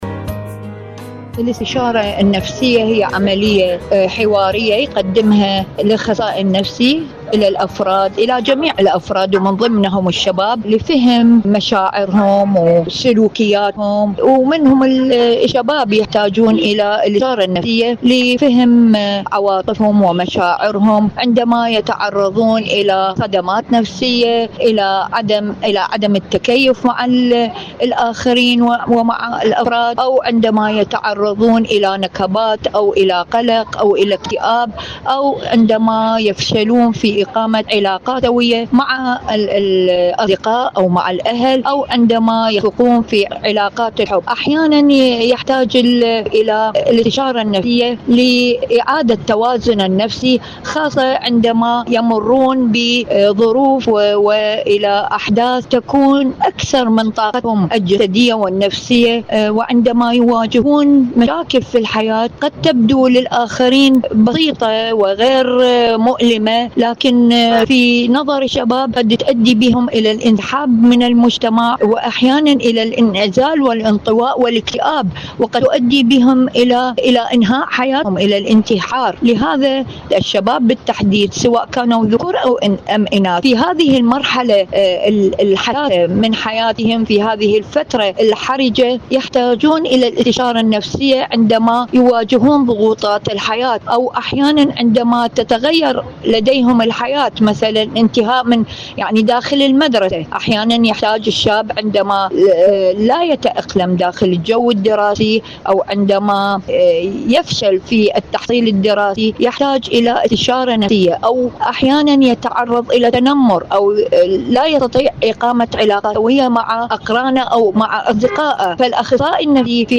الشباب والاستشارة النفسية.. مقابلة